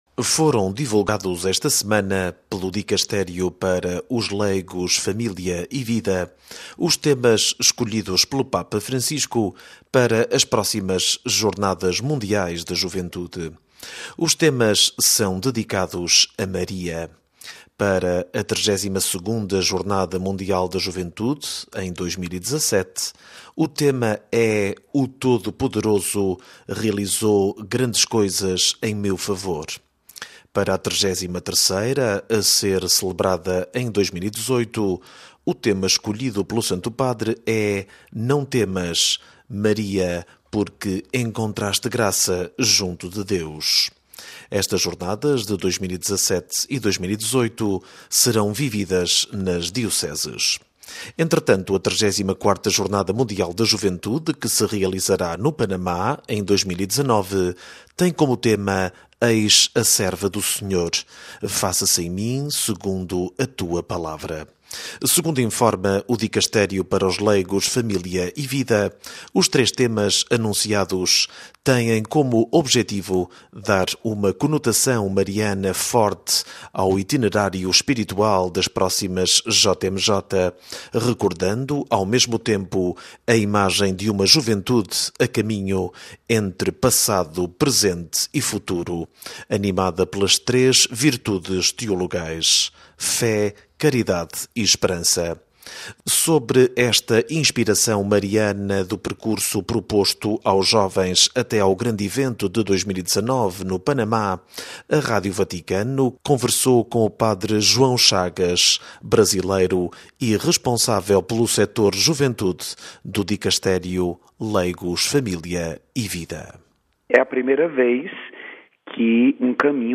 Sobre esta inspiração mariana do percurso proposto aos jovens até ao grande evento de 2019, no Panamá, a Rádio Vaticano conversou